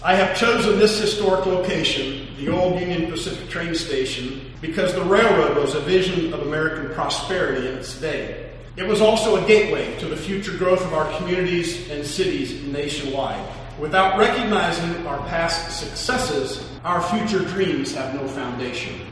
A crowd of about 20 people gathered at the Union Pacific Depot in downtown Manhattan Tuesday